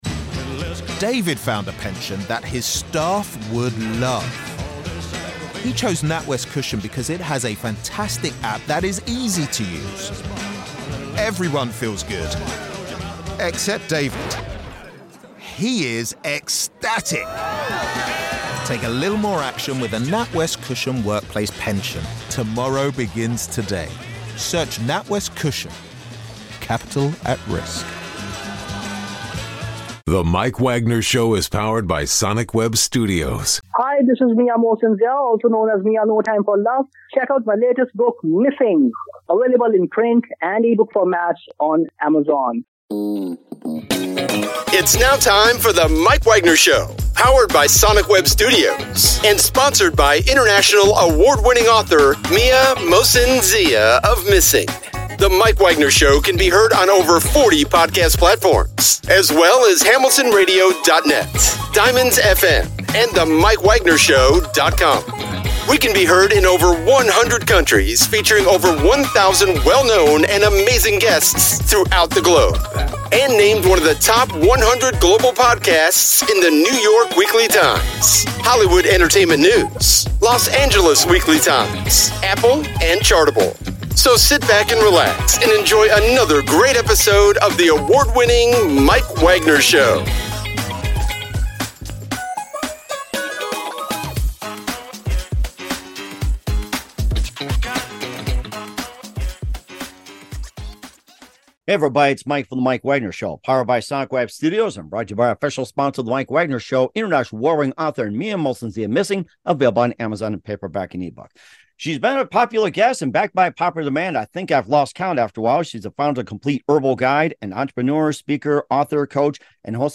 In this captivating interview